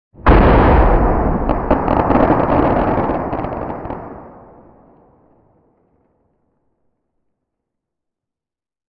bloodclot explode sound.
bloodclot-explode.ogg